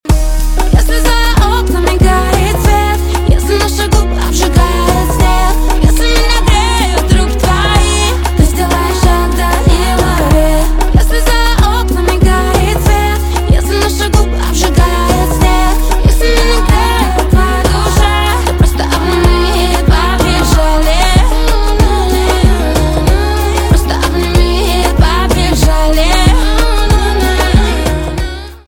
поп
романтические , барабаны